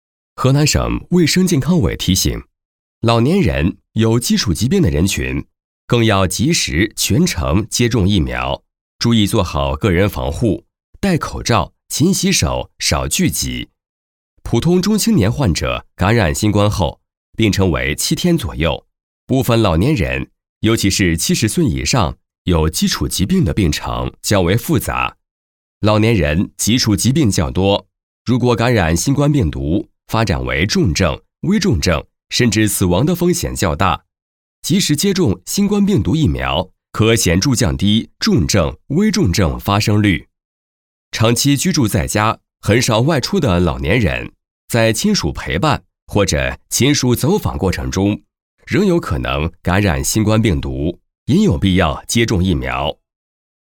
大喇叭-河南省卫生健康委提醒：正确认识感染及如何居家用药.mp3